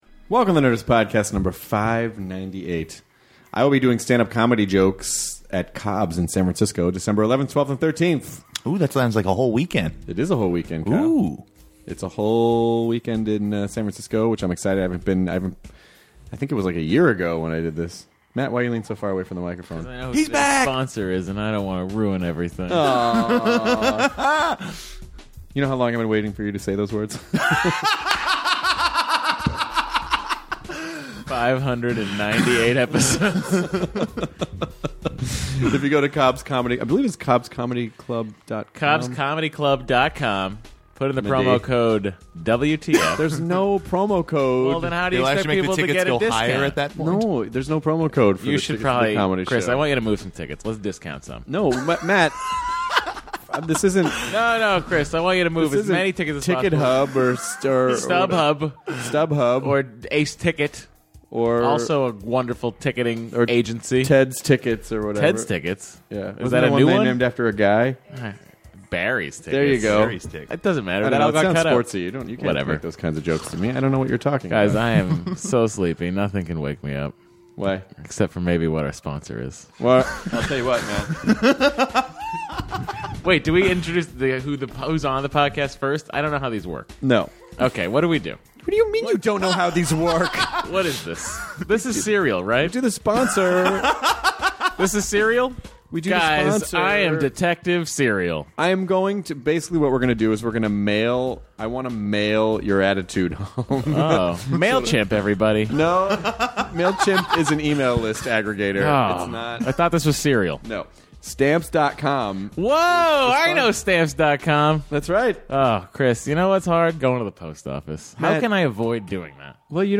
Hilary Swank and Chris have a great conversation despite their voices being shot from busy days! She talks about going from The Karate Kid to Boys Don’t Cry, how she molded her career since then and her new film The Homesman.